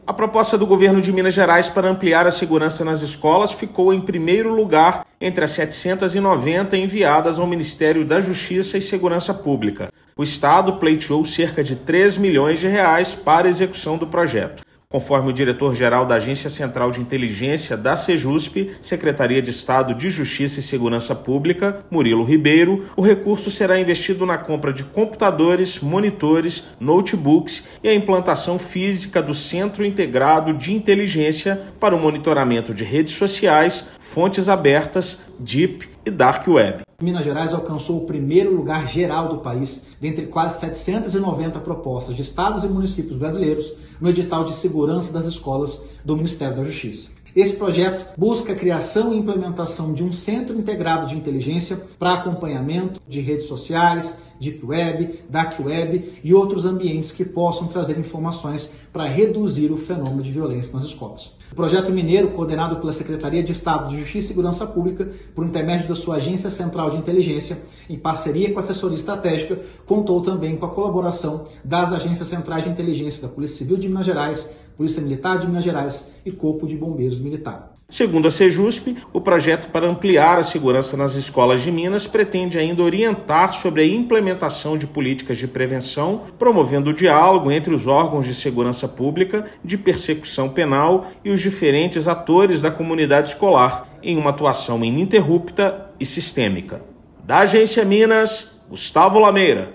Iniciativa da Sejusp busca criação de Centro Integrado de Inteligência para monitorar redes sociais, fontes abertas, deep e dark web. Ouça matéria de rádio.